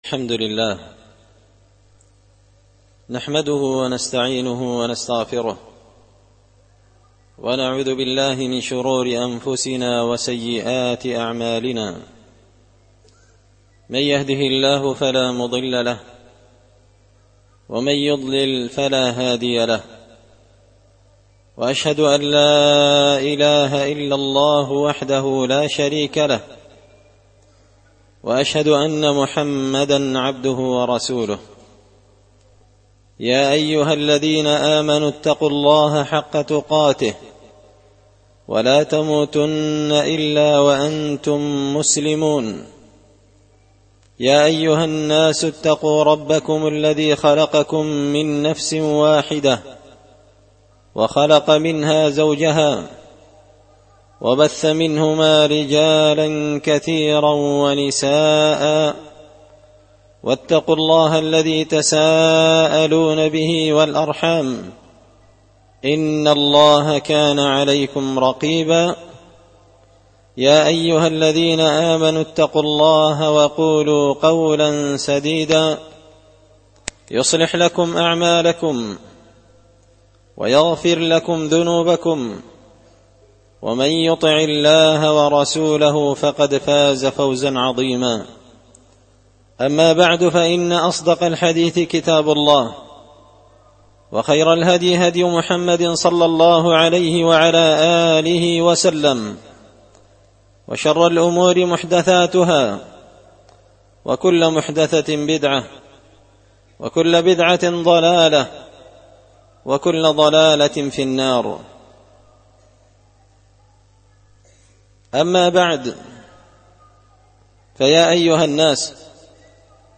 خطبة جمعة بعنوان – إن جاء كم فاسق بنبإ فتبينوا
دار الحديث بمسجد الفرقان ـ قشن ـ المهرة ـ اليمن